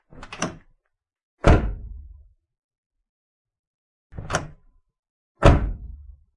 车辆声音 " 福特F150车门 - 声音 - 淘声网 - 免费音效素材资源|视频游戏配乐下载
两个福特车门的声音在我的随身听上录制。数字增强。